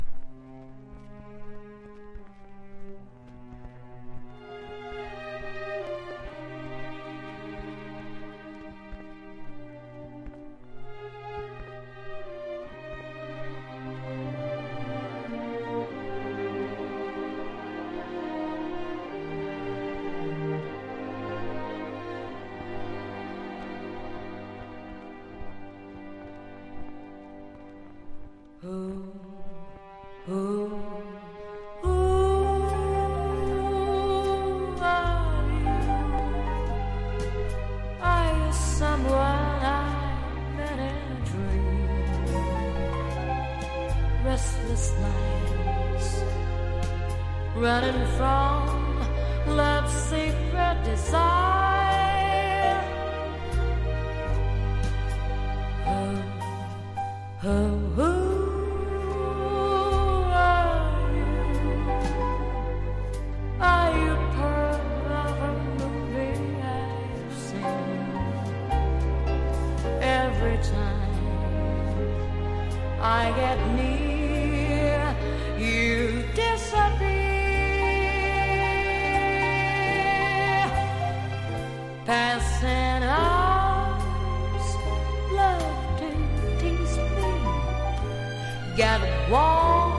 CITY POP / AOR# 和ジャズ# 和モノ